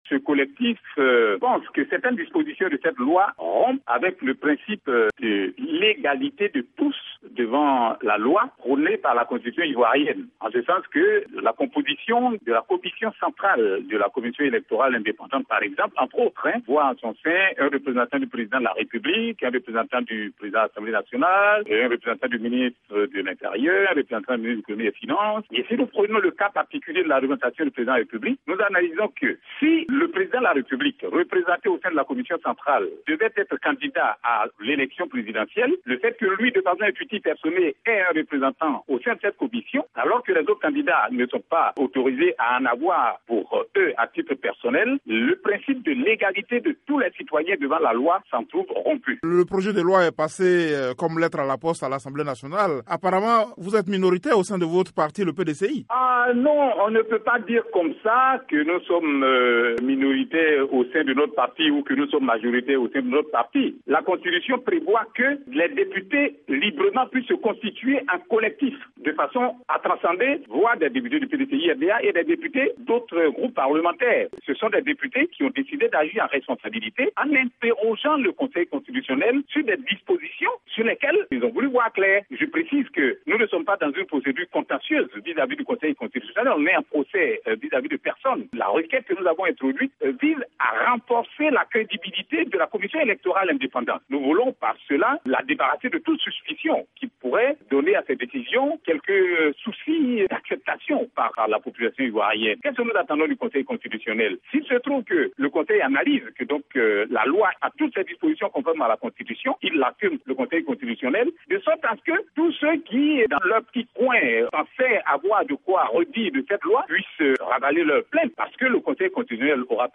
joint à Abidjan